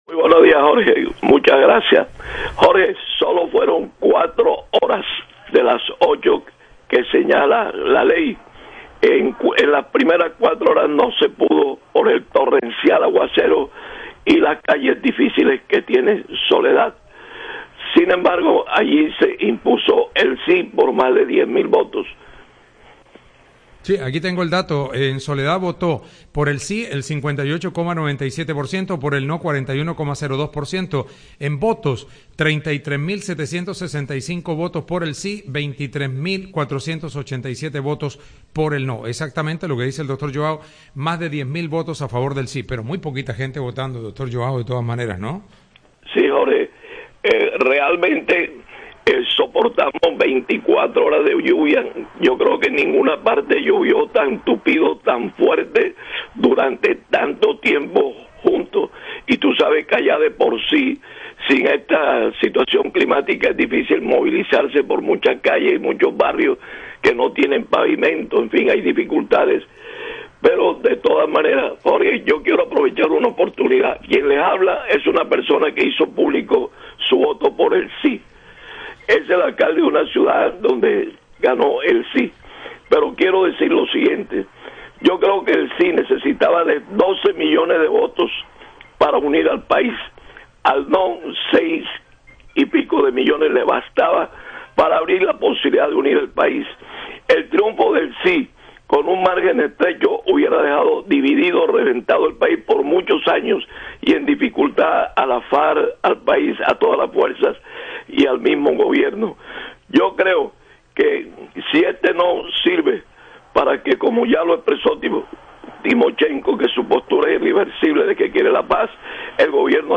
El alcalde de Soledad, Joao Herrera, afirmó que en su municipio ganó el Sí en favor de la paz de Colombia.